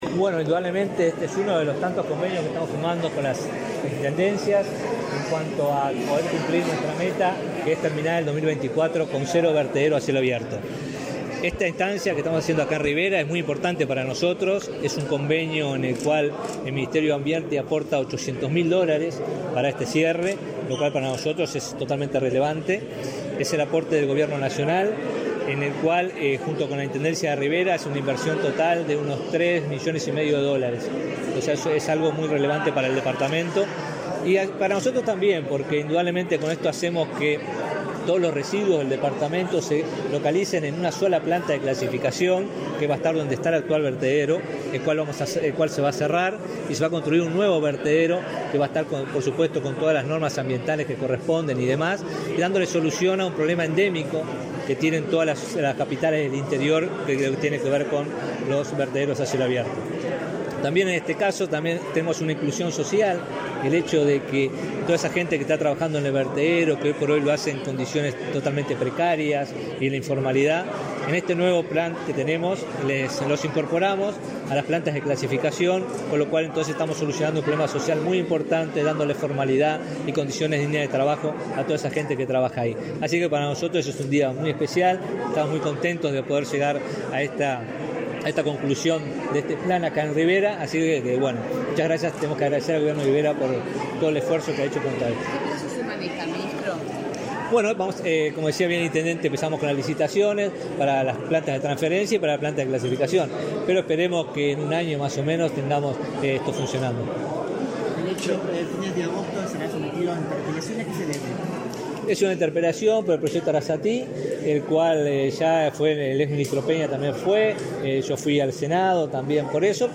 Declaraciones a la prensa del ministro de Ambiente, Robert Bouvier